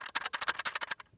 wagic/projects/mtg/bin/Res/sound/sfx/artifact.wav
-reduced SFX Quality.